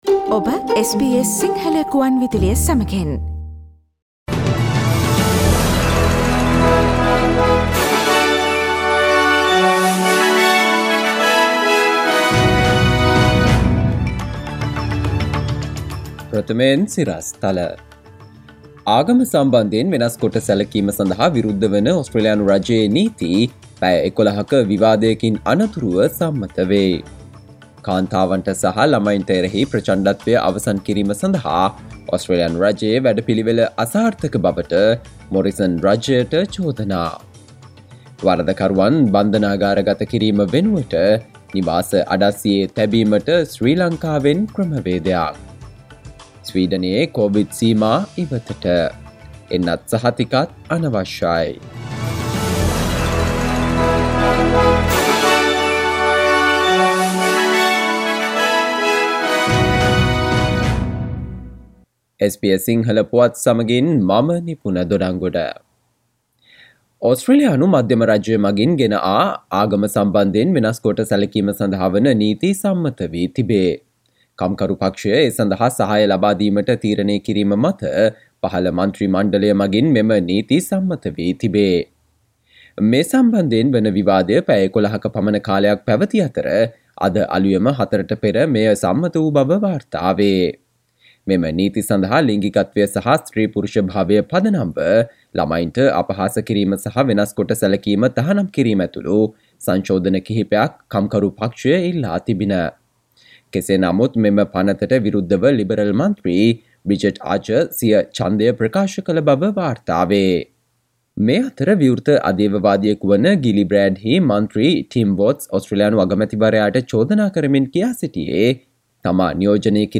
සවන්දෙන්න 2022 පෙබරවාරි 10 වන බ්‍රහස්පතින්දා SBS සිංහල ගුවන්විදුලියේ ප්‍රවෘත්ති ප්‍රකාශයට...